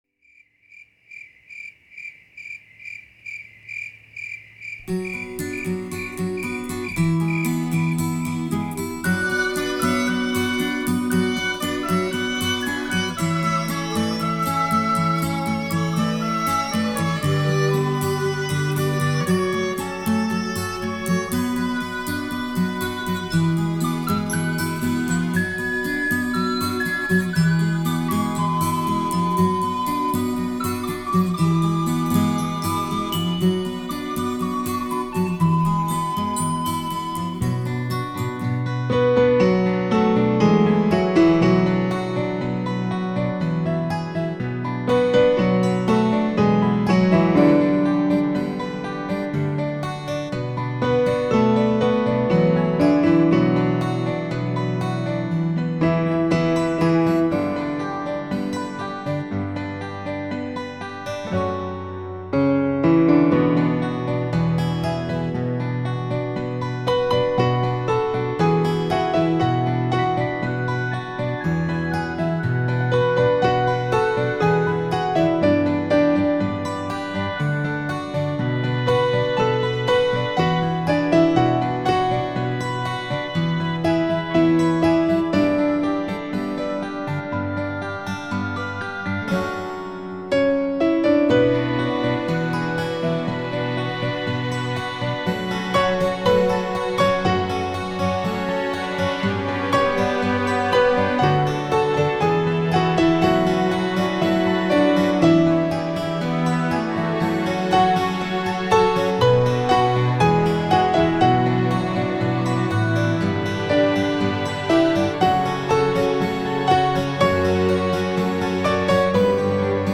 休闲减压音乐
被誉为“乐器之王”钢琴具有优美纯净的声学品质，典雅新颖的造型，准确稳定的音律。
这里精选了13首钢琴独奏及协奏曲，完美的音效，精彩的演奏，给您超值的音乐享受。